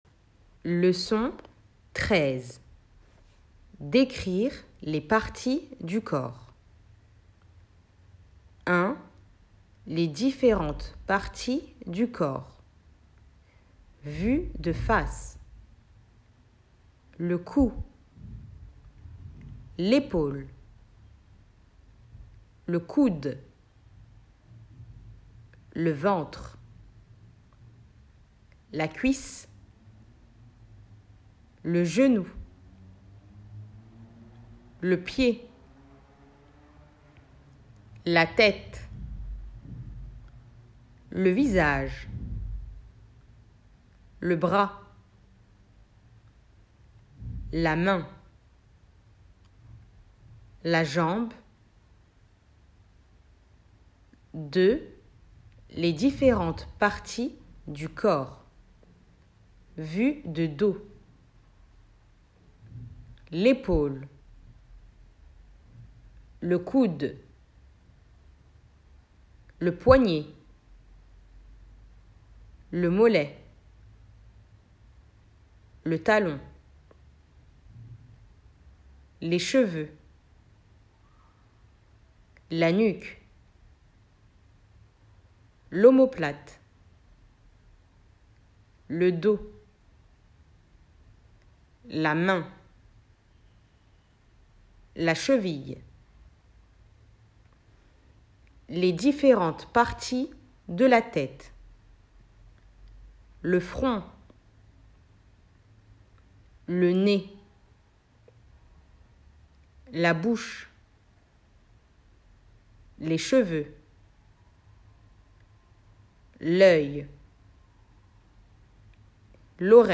Lecon-13-les-parties-du-corps.mp3